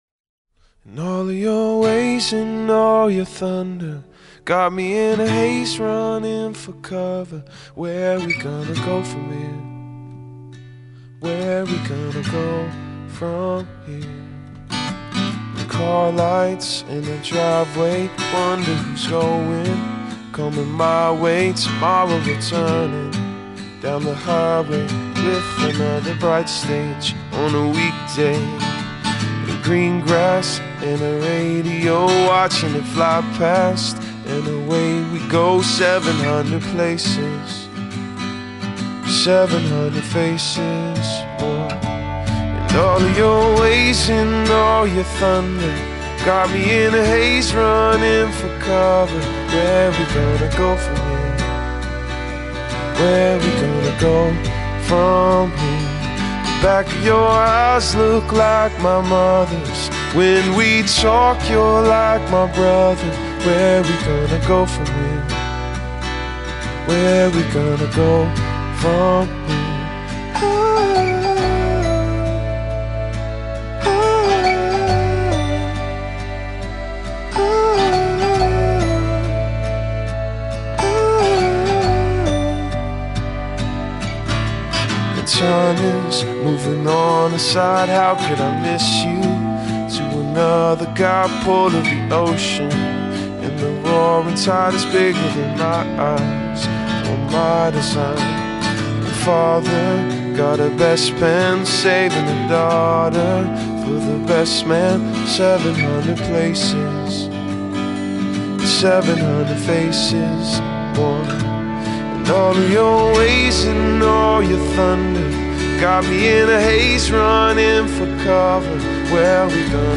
Posted in adult alternative on March 20th, 2007 5 Comments »